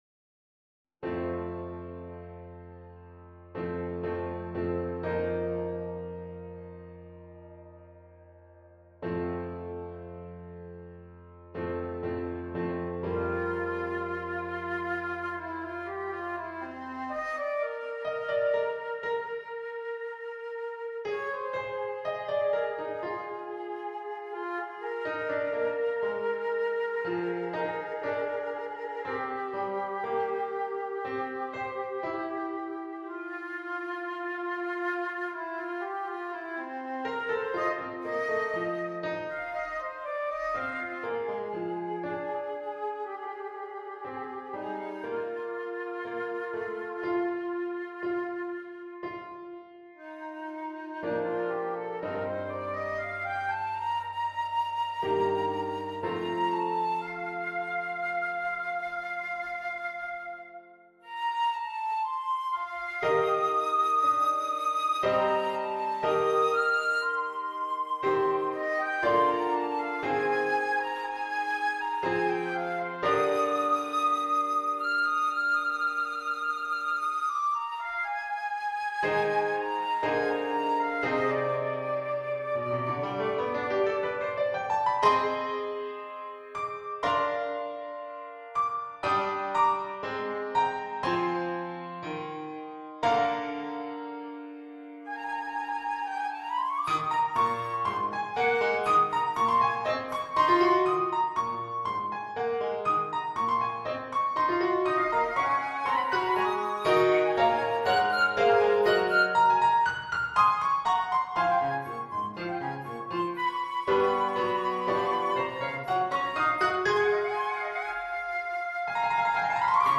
Flute with Piano accompaniment.